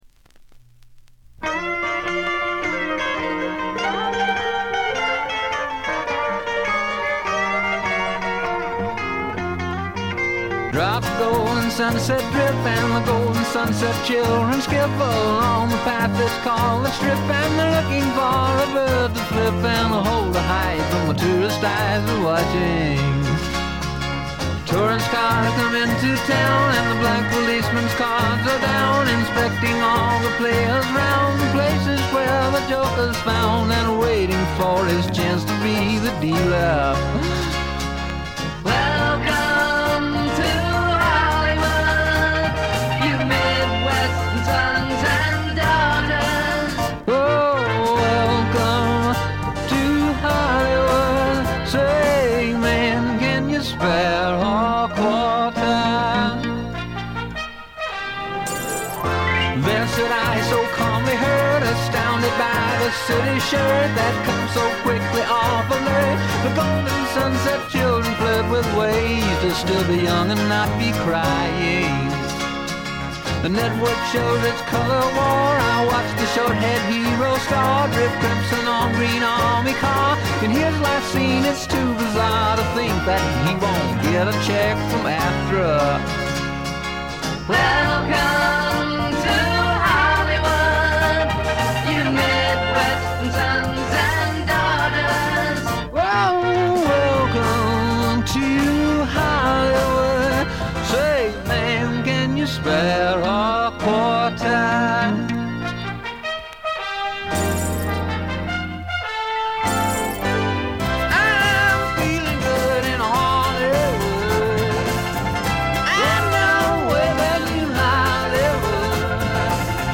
軽微なバックグラウンドノイズ、少しチリプチ。
60年代ポップ・サイケな色彩でいろどられたサージェント・ペパーズな名作！！
試聴曲は現品からの取り込み音源です。